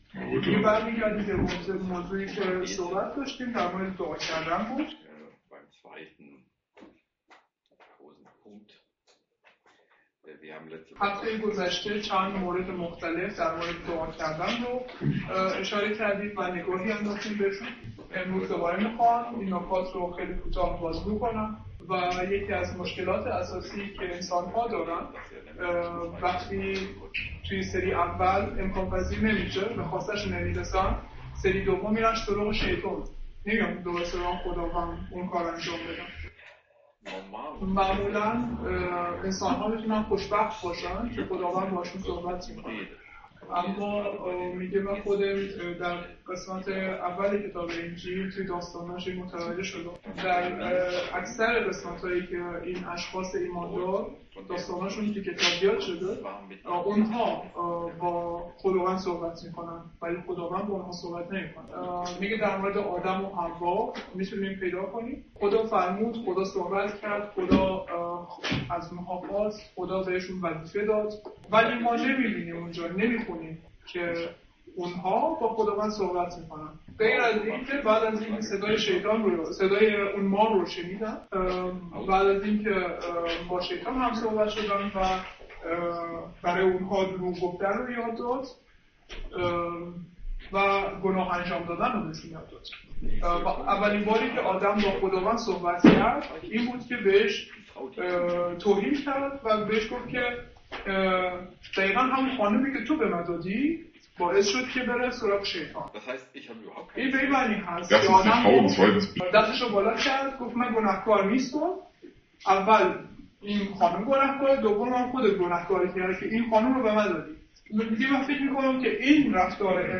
Mose 9 zum Thema Gebet Noah| Übersetzung in Farsi